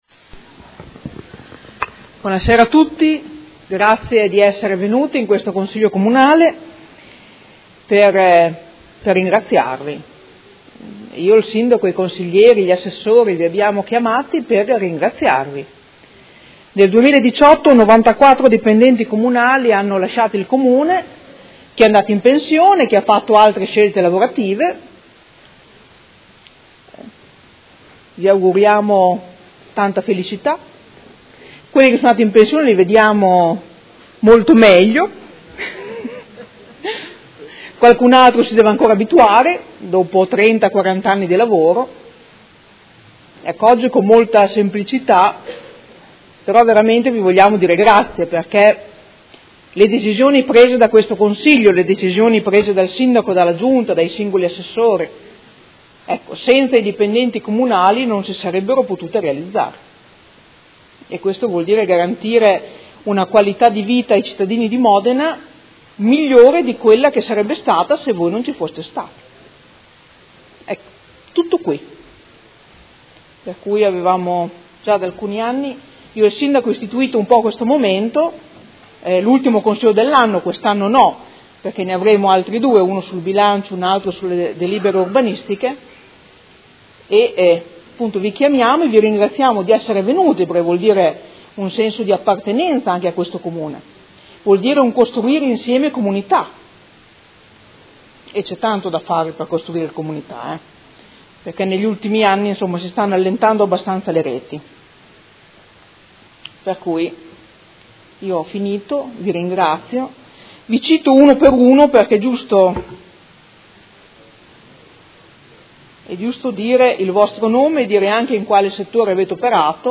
Seduta del 13/12/2018. Riprende i lavori con lo scambio degli auguri.